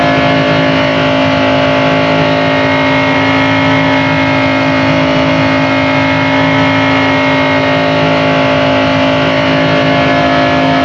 rr3-assets/files/.depot/audio/Vehicles/v8_nascar/nascar_high_8000.wav
nascar_high_8000.wav